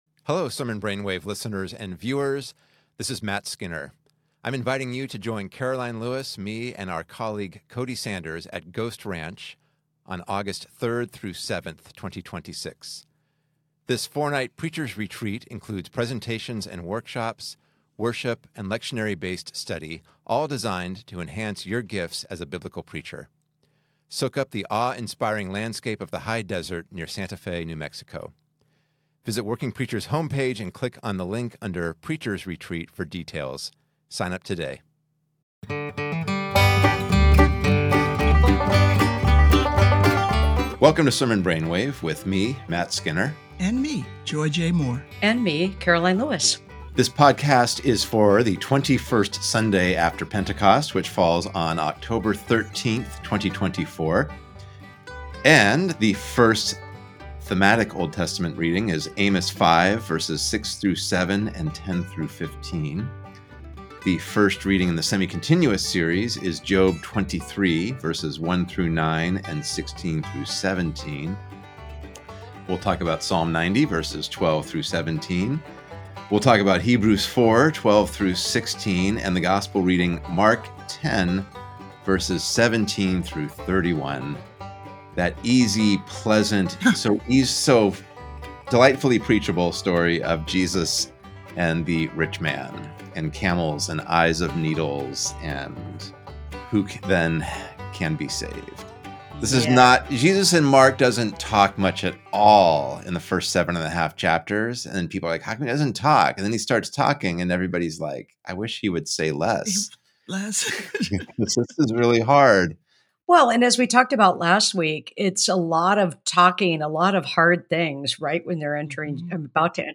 In this episode, you will explore themes of wealth, possessions, and the call to follow Jesus. Our hosts delve into the honesty and lament in Job, the tension between God's presence and absence, and the promise of resurrection. The conversation emphasizes solidarity, community empowerment, and the boldness to approach God.